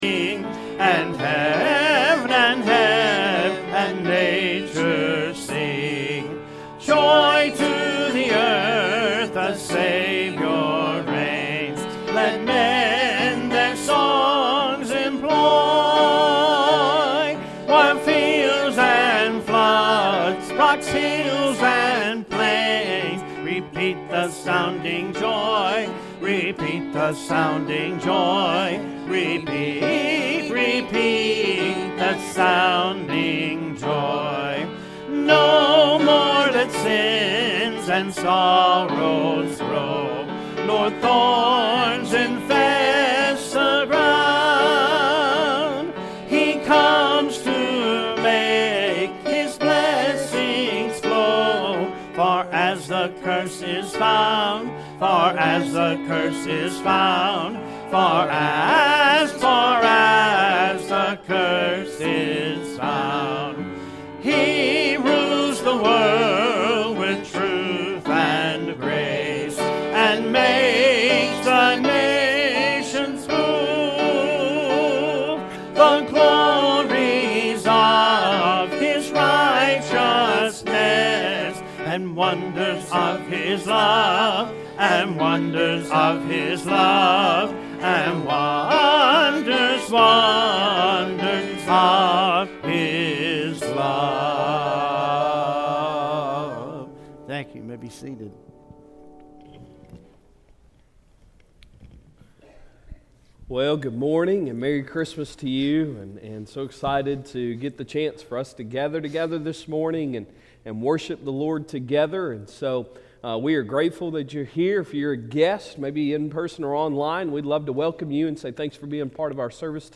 Sunday Sermon December 25, 2022
Christmas Day Service